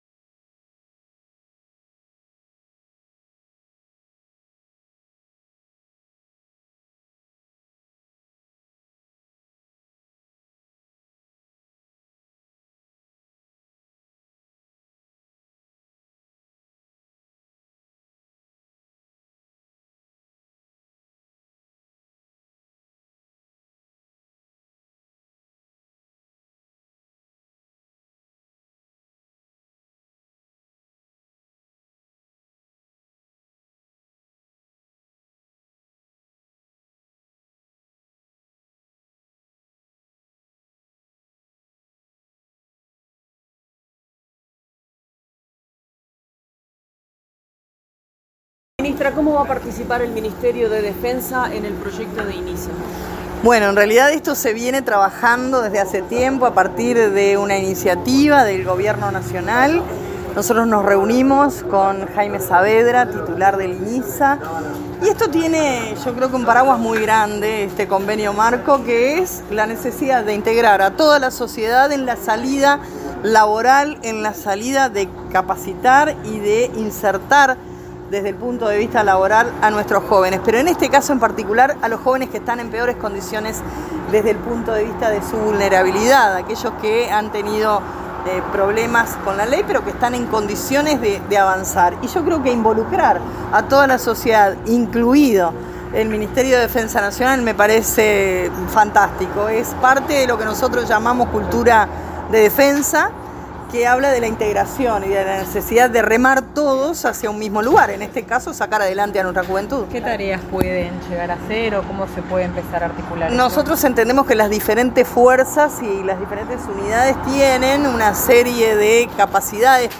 Declaraciones de la ministra de Defensa, Sandra Lazo
Declaraciones de la ministra de Defensa, Sandra Lazo 26/11/2025 Compartir Facebook X Copiar enlace WhatsApp LinkedIn En el marco de la entrevista del presidente Orsi en la instancia Desayunos Búsqueda, la ministra de Defensa Nacional, Sandra Lazo, efectuó declaraciones a la prensa.